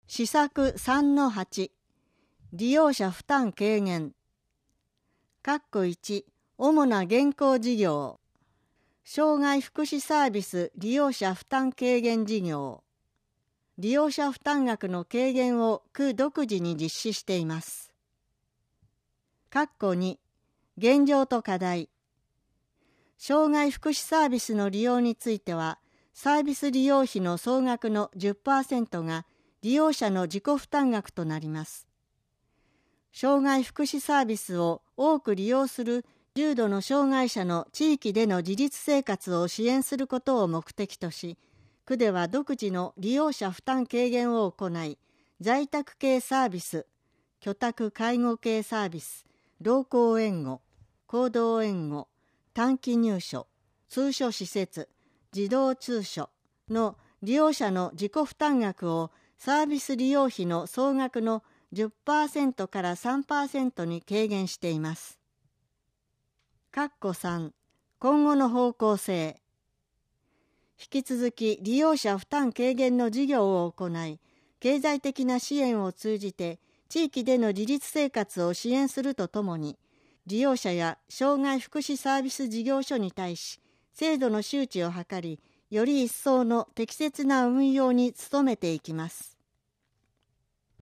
計画の音声版（デイジー版）CDを区役所の障害者福祉課及び区立図書館で貸出しています。